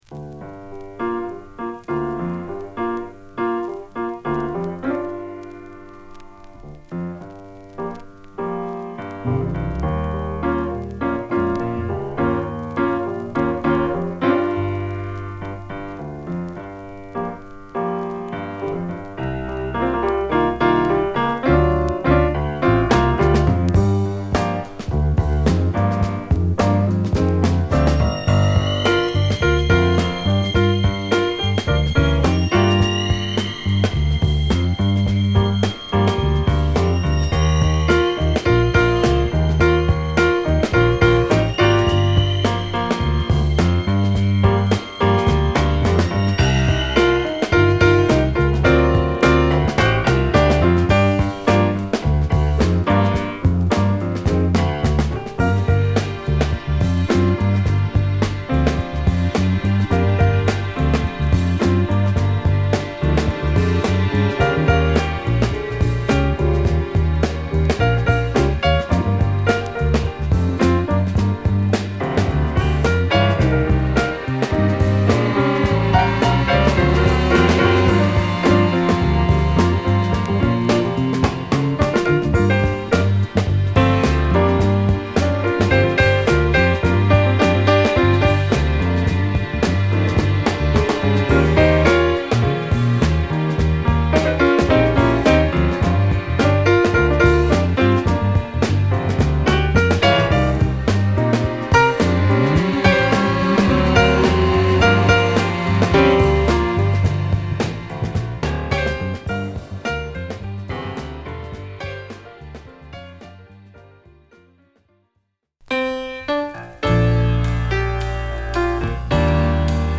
＊A1頭ノイズ小有ります